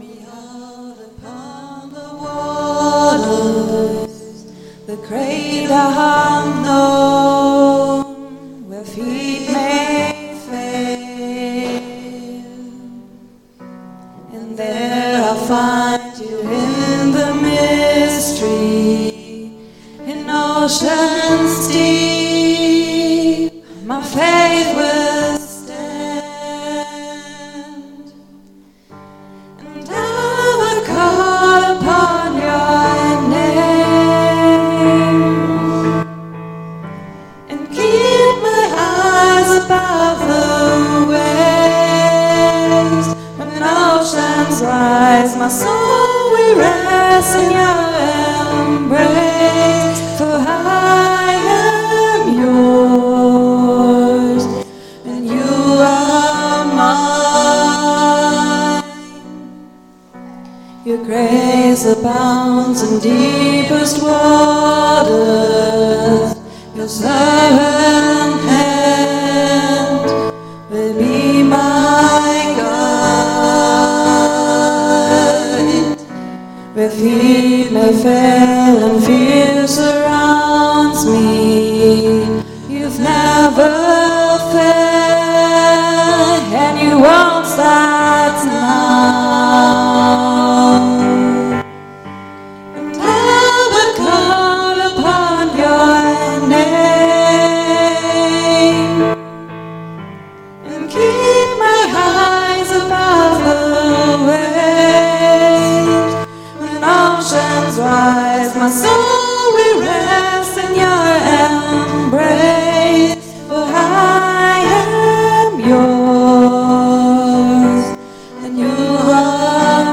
Aktuelle Predigt